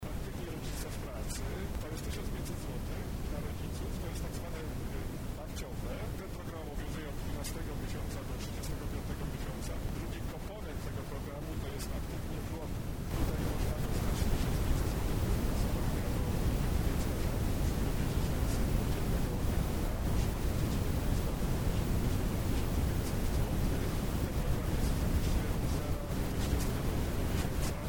Jak przypomniał Piotr Lachowicz, poseł Koalicji Obywatelskiej z Nowego Sącza, „Aktywny Rodzic” to program, z którego można skorzystać w ramach trzech różnych możliwości.